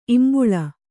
♪ imbuḷa